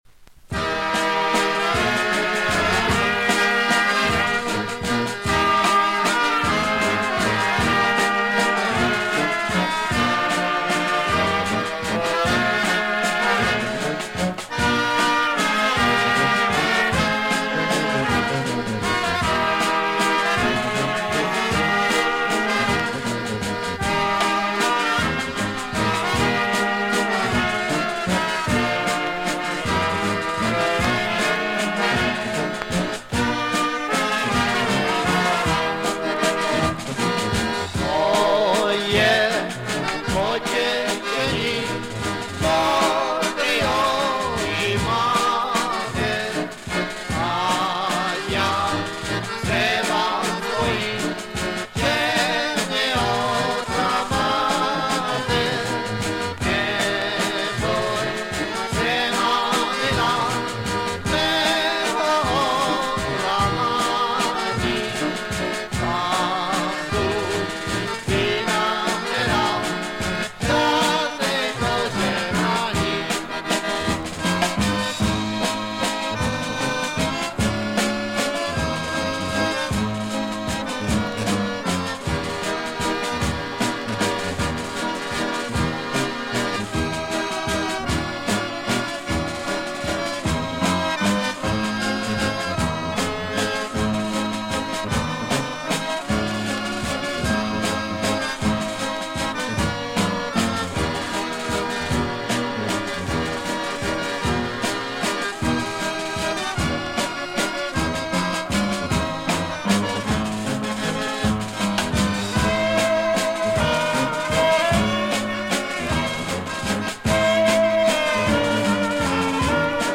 Commentary 7.